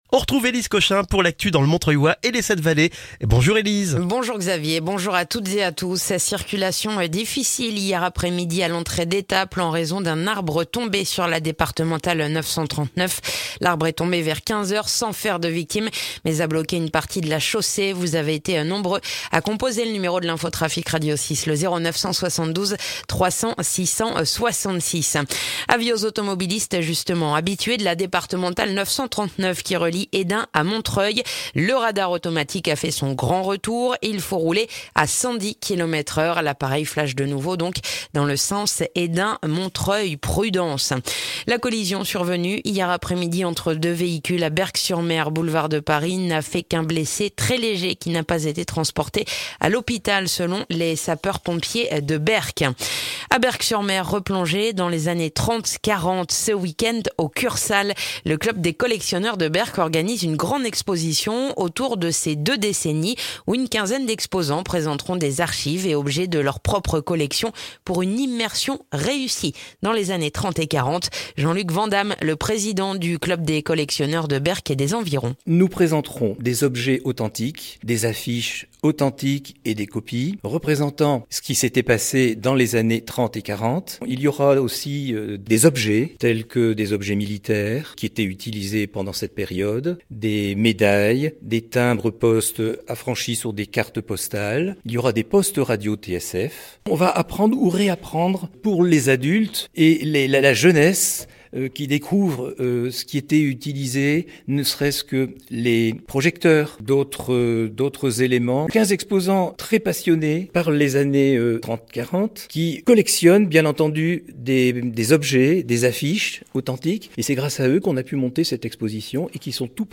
Le journal du jeudi 10 novembre dans le montreuillois